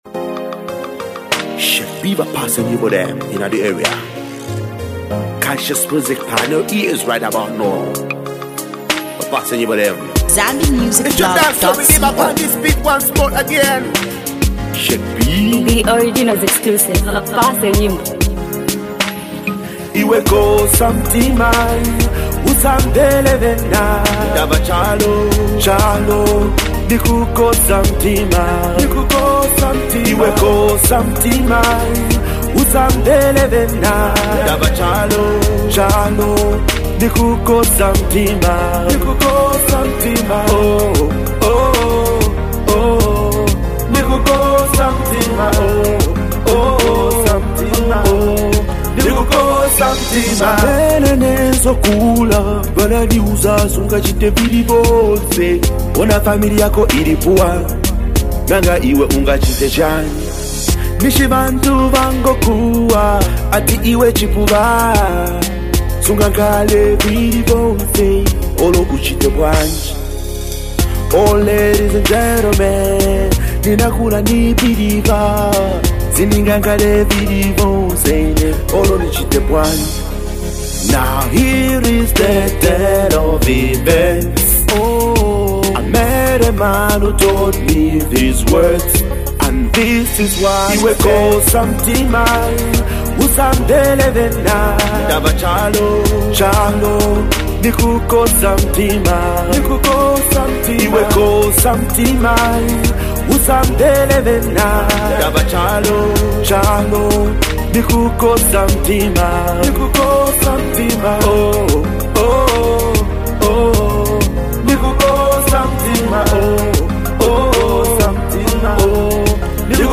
Zam-Dancehall